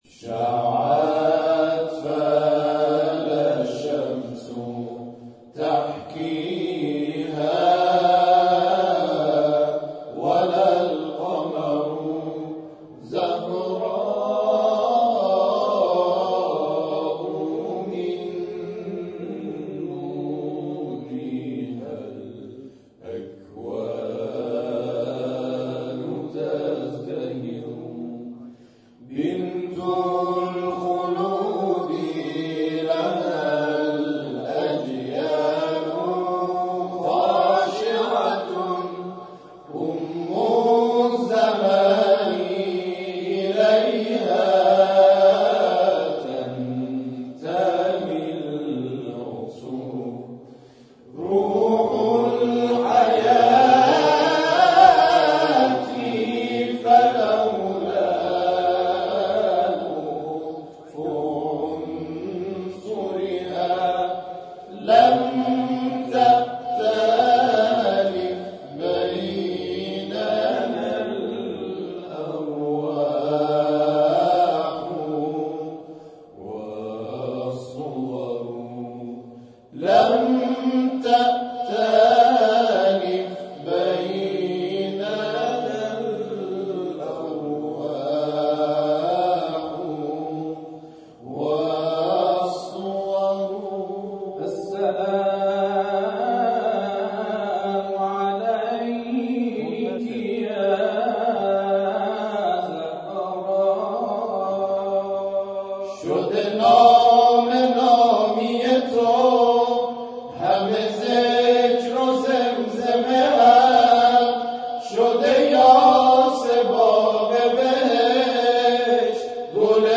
گروه تواشیح شمس